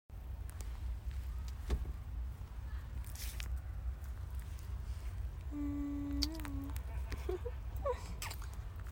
muahh 😚💕 sound effects free download